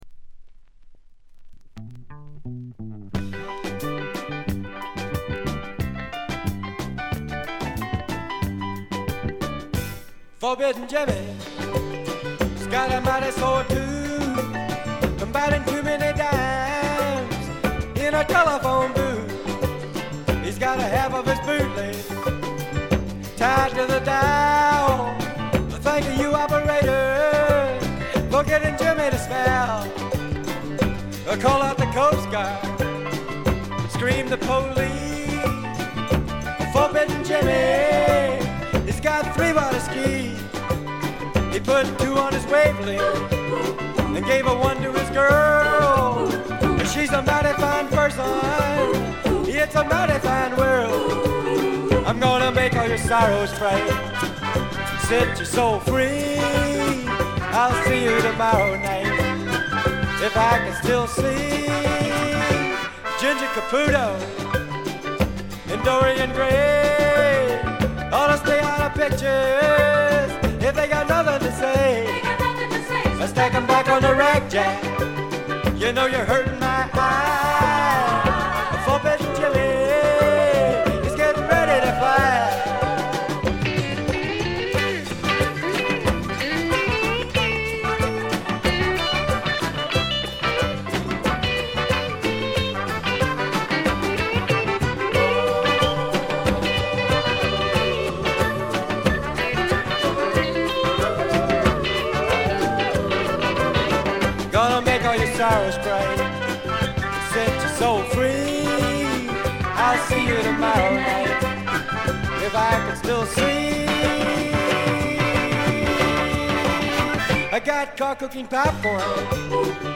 部分試聴ですが軽微なチリプチ少々程度。
試聴曲は現品からの取り込み音源です。
vocals, acoustic guitar